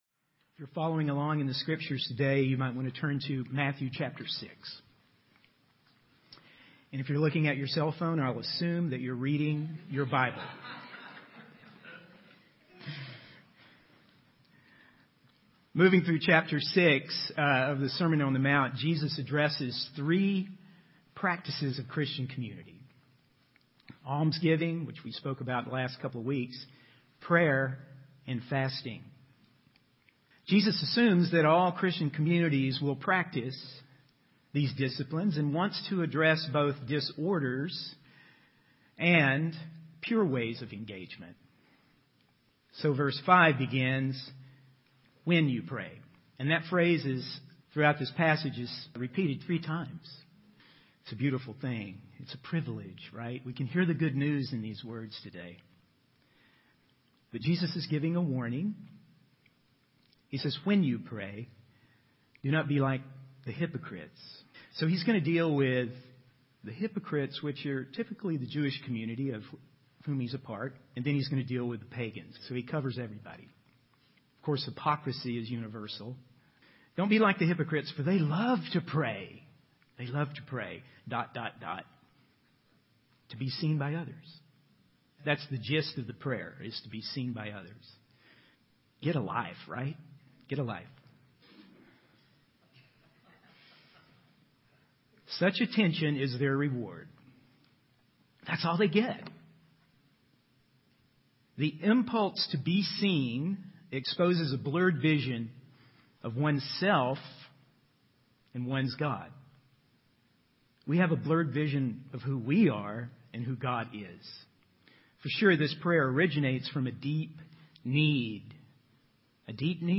In this sermon, the speaker emphasizes the importance of being in the presence of God through prayer. He encourages listeners to close the door on distractions and anxieties and simply rest in God's love and trust. The sermon is based on Matthew chapter six, where Jesus addresses the practices of Christian community, including arms giving, prayer, and fasting.